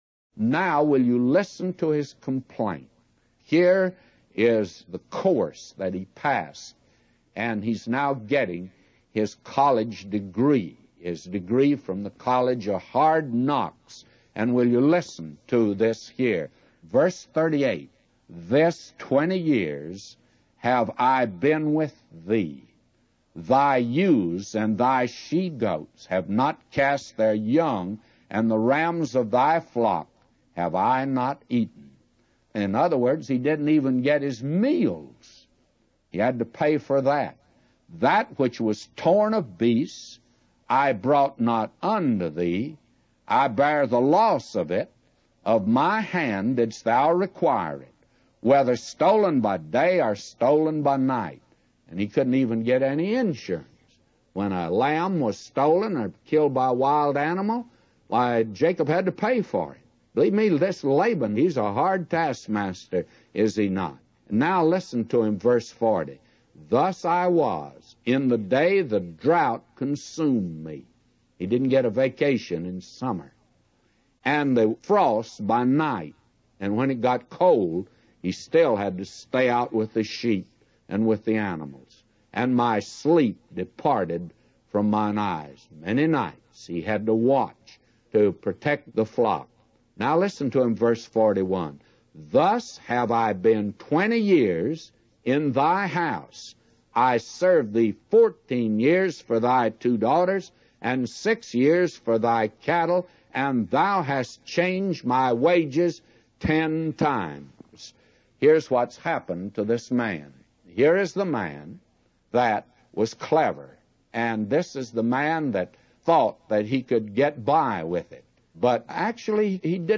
A Commentary By J Vernon MCgee For Genesis 31:38-999